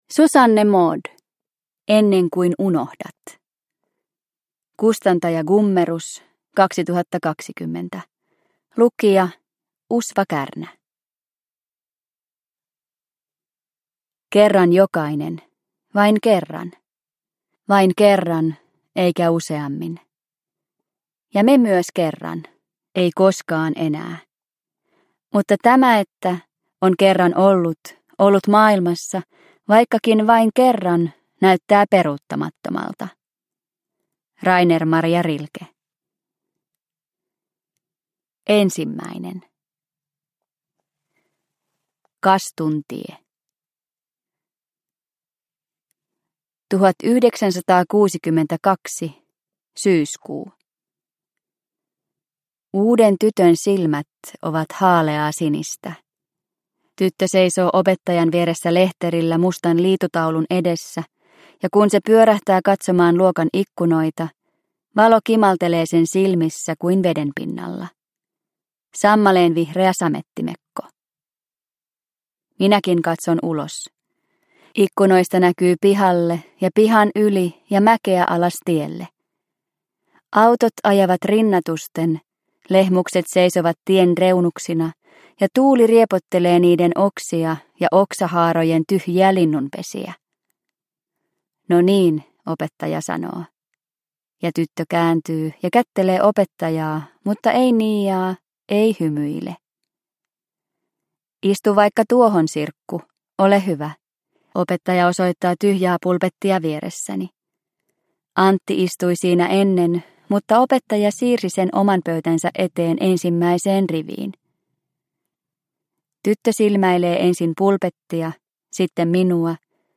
Ennen kuin unohdat – Ljudbok – Laddas ner